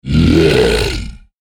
クリーチャーボイス16.mp3